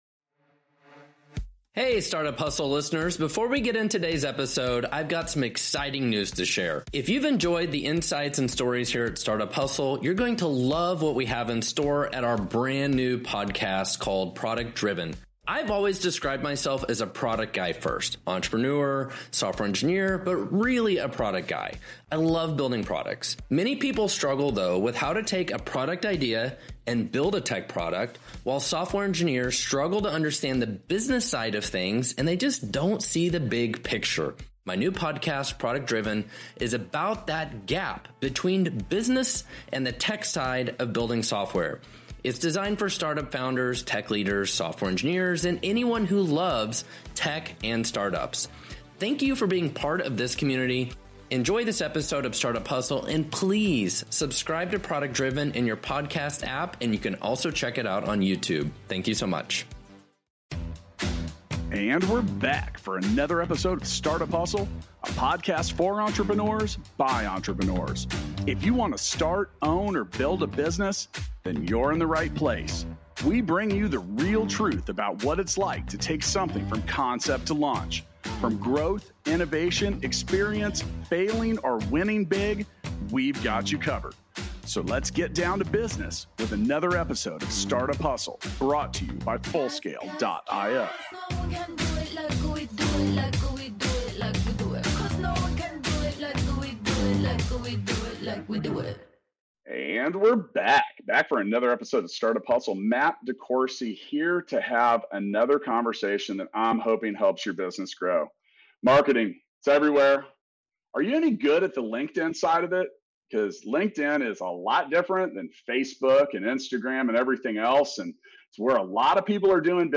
We had a mic problem on this one but decided to publish anyway.